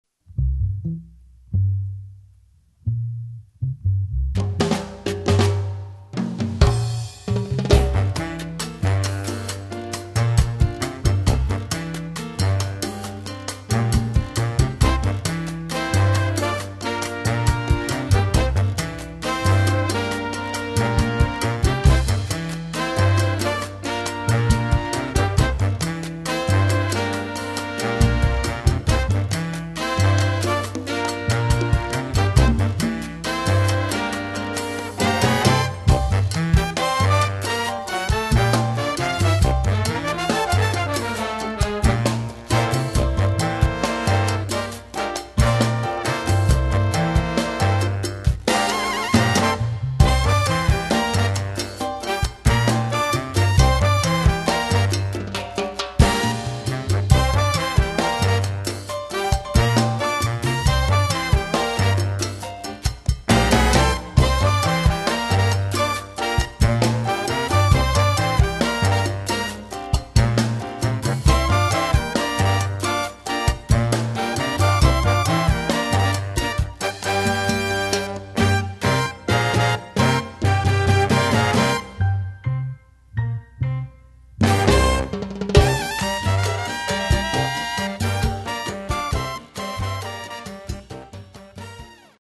Category: combo (nonet)
Style: cha cha